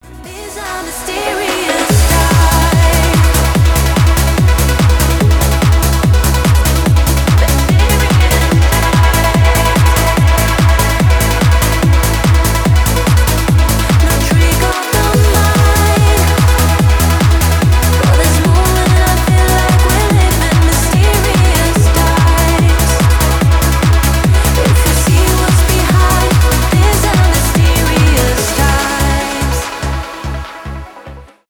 клубные
транс